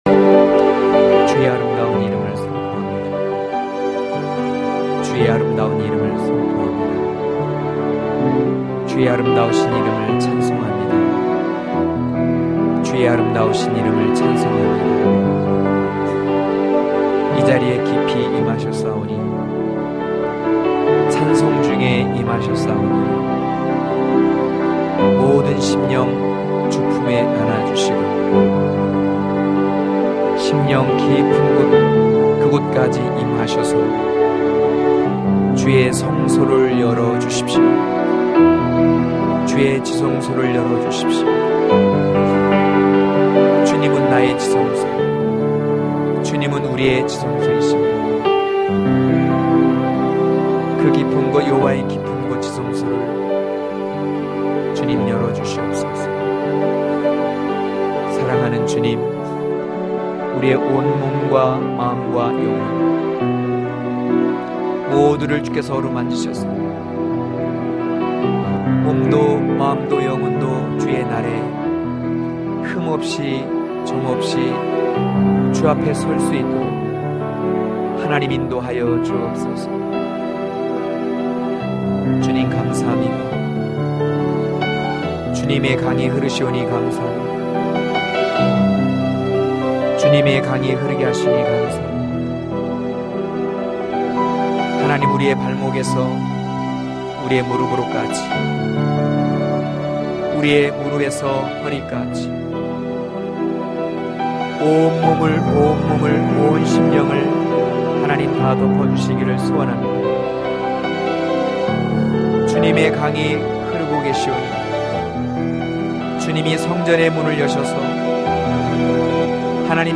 강해설교 - 11.몰약과 유향의 작은 산(아4장1-6절)